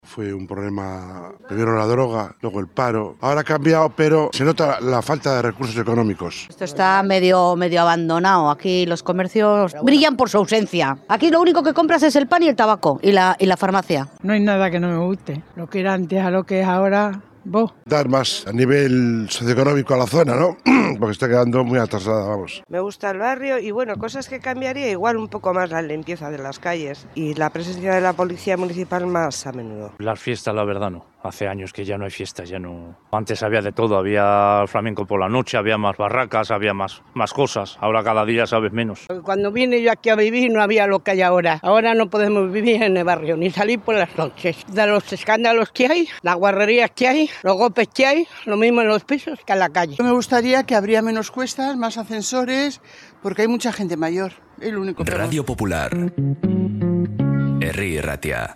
En 'Bilbao al habla' conocemos la opinión de los vecinos de Otxarkoaga